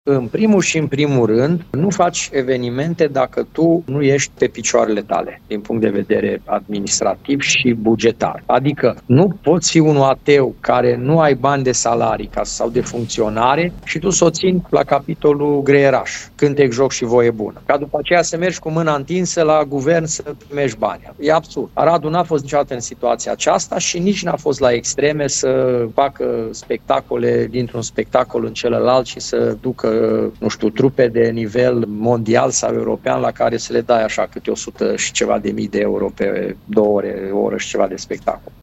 Primarul Aradului, Călin Bibarț, spune că a primit sugestii să renunțe la concertele care sărbătoresc orașul, însă le-a respins pentru că adminstrația locală nu a exagerat cheltuielile pentru spectacole publice.